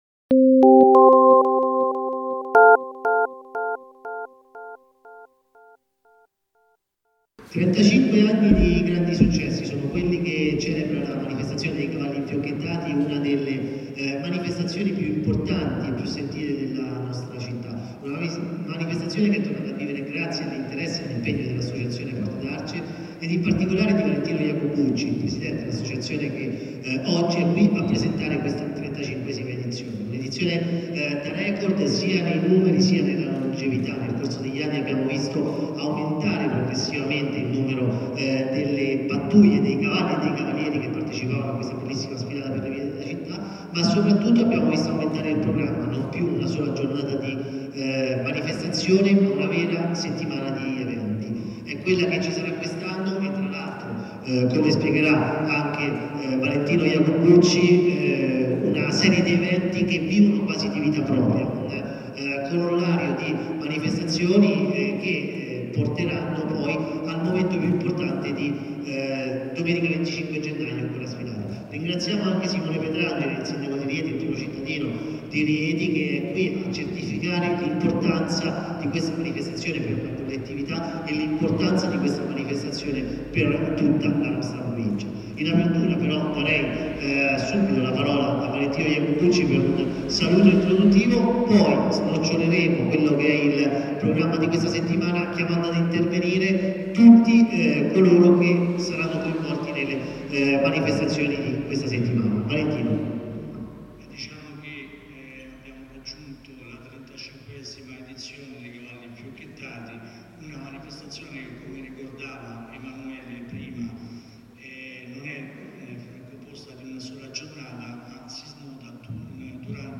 È stata presentata sabato 17 gennaio alle 12 presso l’Auditorium Dei Poveri la 35esima edizione dei Cavalli Infiocchettati.
Conferenza-Stampa-Cavalli-Infiocchettati-2015.mp3